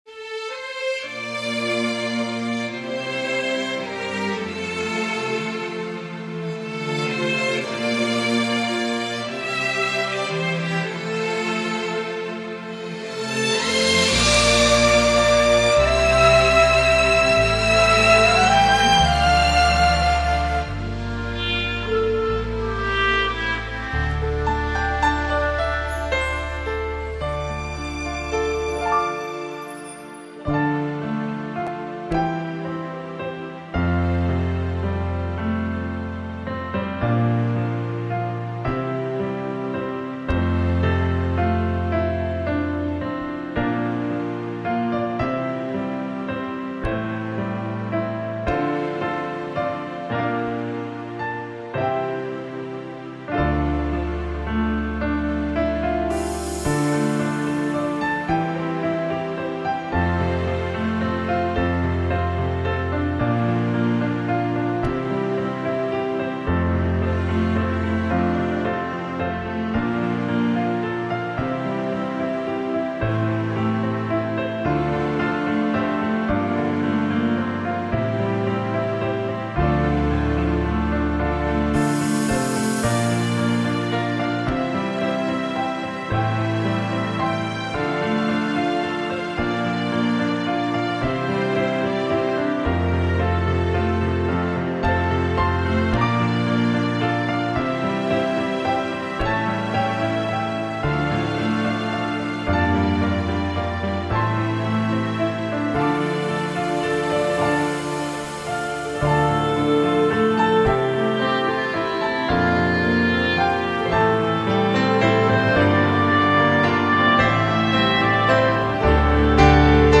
F调伴奏